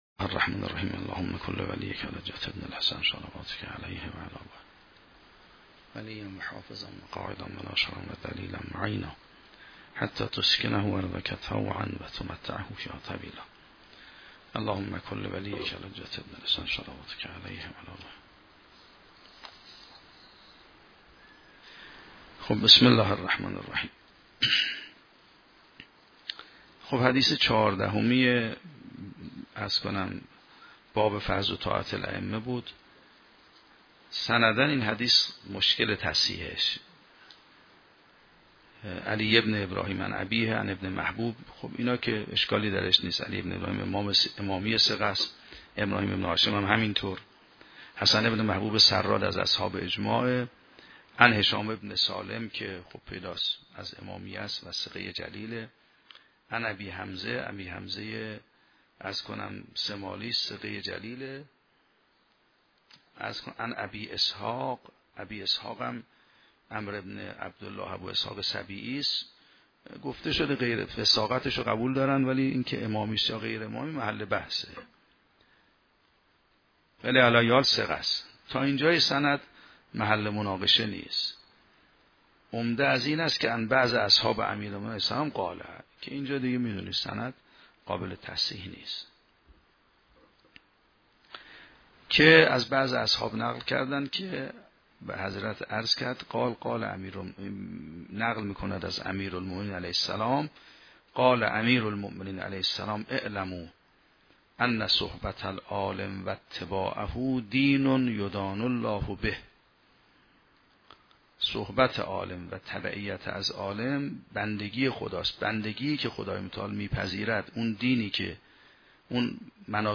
شرح و بررسی کتاب الحجه کافی توسط آیت الله سید محمدمهدی میرباقری به همراه متن سخنرانی ؛ این بخش : مرسله ابی اسحاق و خطبه امیرالمومنین؛ مصاحبت قلبی و اتباع امام در دینداری